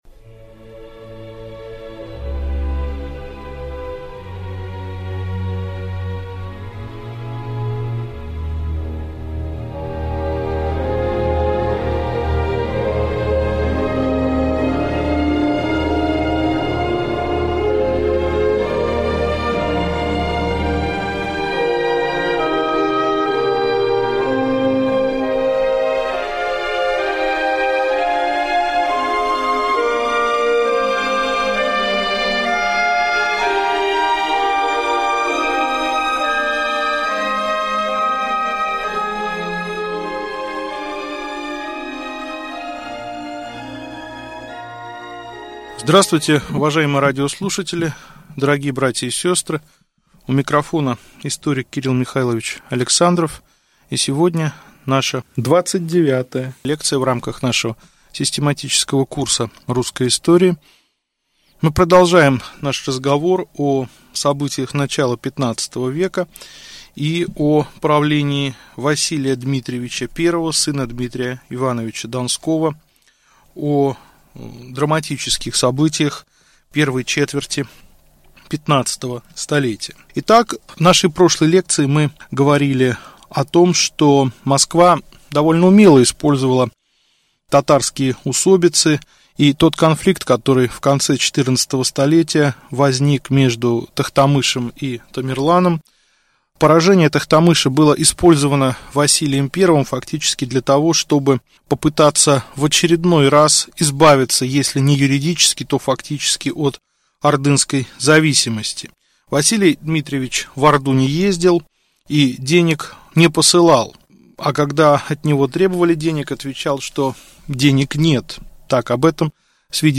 Аудиокнига Лекция 29. Вел. кн. Василий I Дмитриевич. Русское общество в конце XIV – начале XV вв | Библиотека аудиокниг